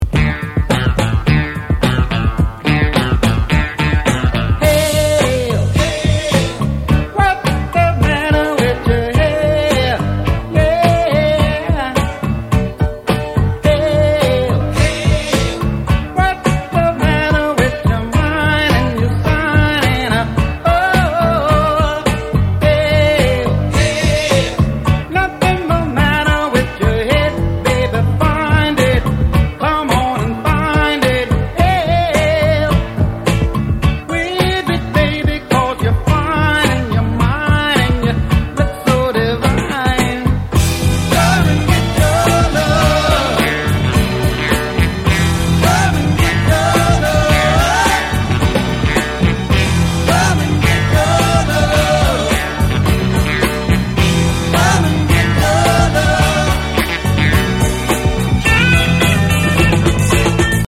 • Качество: 128, Stereo
позитивные
мужской вокал
веселые
ретро